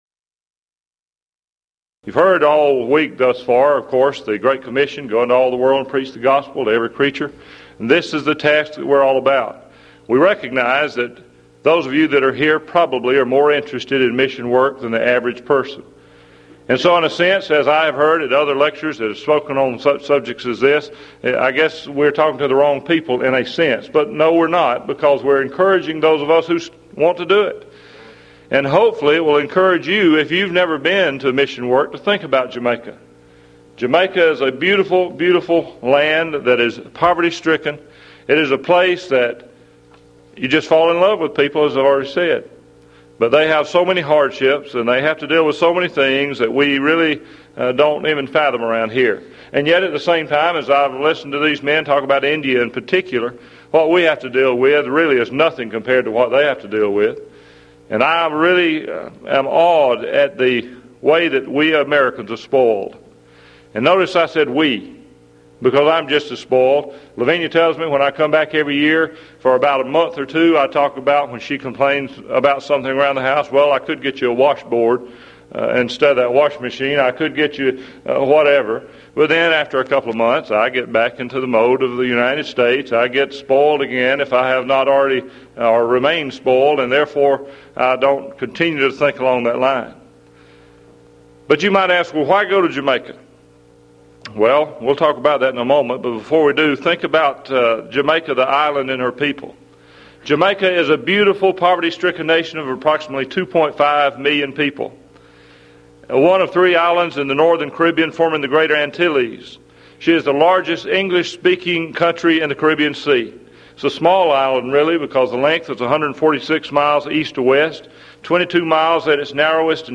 Event: 1994 Mid-West Lectures
lecture